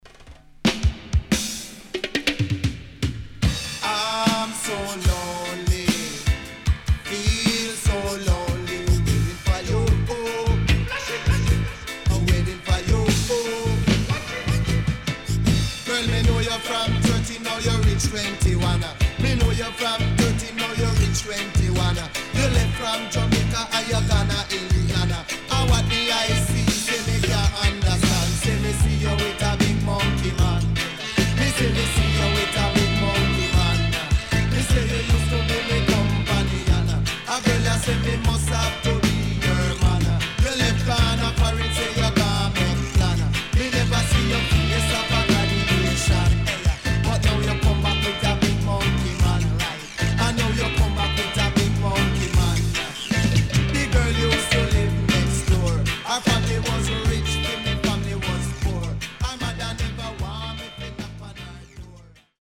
SIDE A:少しチリノイズ入りますが良好です。